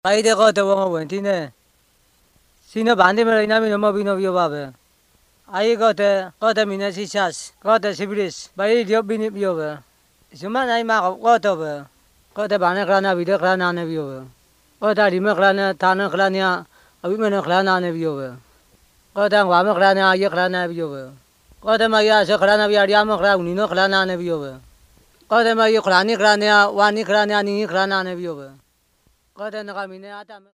Words of Life recordings contain short Bible stories, evangelistic messages and songs. They explain the way of salvation and give basic Christian teaching. Most use a storytelling approach. These are recorded by mother-tongue speakers